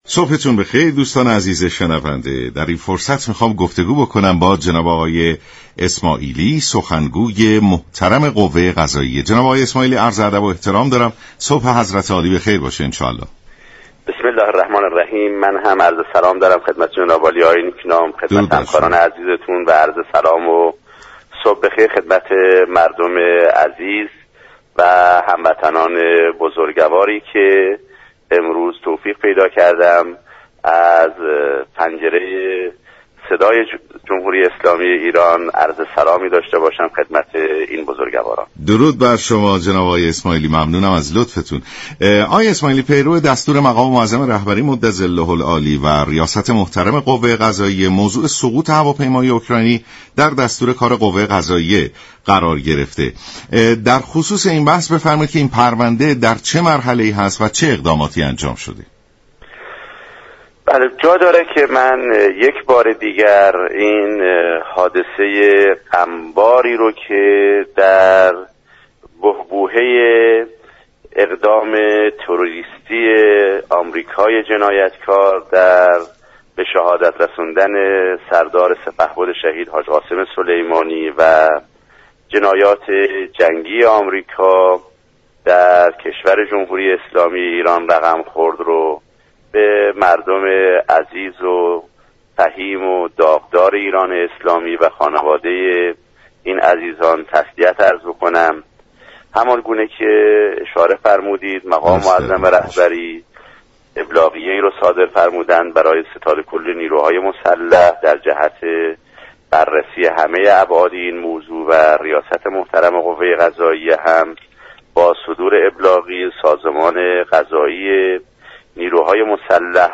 به گزارش شبكه رادیویی ایران، «غلامحسین اسماعیلی» سخنگوی قوه قضائیه در برنامه «سلام صبح بخیر» رادیو ایران درباره اقدامات قوه قضاییه در رسیدگی به پرونده سقوط هواپیمای اوكراینی گفت: پیرو دستور و ابلاغیه مقام معظم رهبری به ستاد كل نیروهای مسلح جهت بررسی ابعاد دقیق این ماجرا، رییس قوه قضاییه با صدور ابلاغیه ای، سازمان قضایی نیروهای مسلح را مامور پیگیری این ماجرا كرده است.